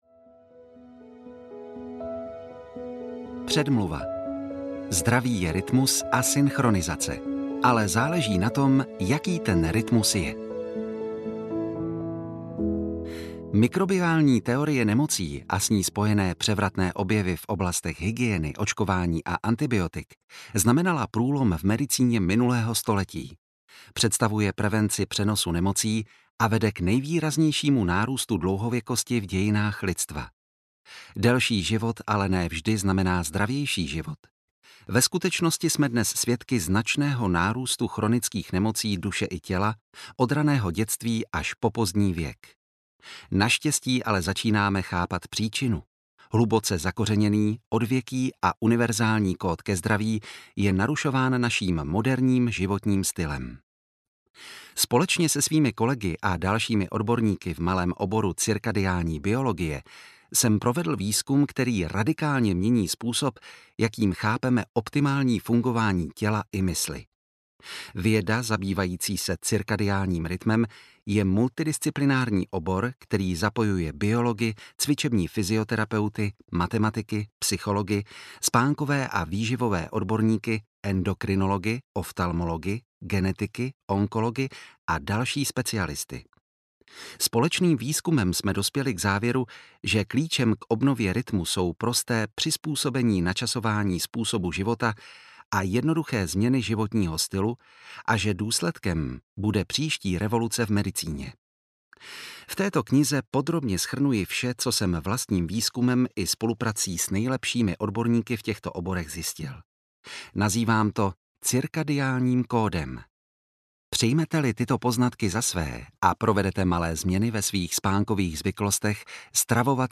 Cirkadiánní kód audiokniha
Ukázka z knihy